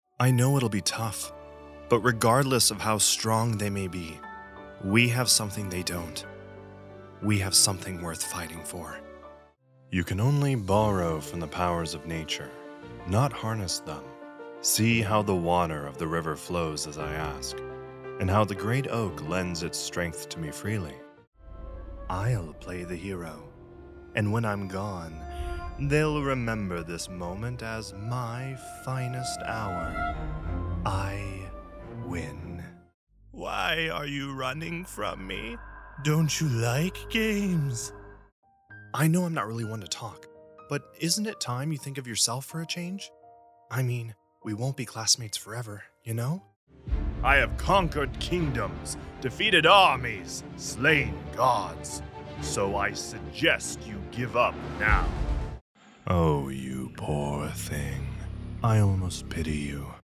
Professional Male Voice Over Talent
a professional male voice actor specializing in voice over narration, animation, video games, and more!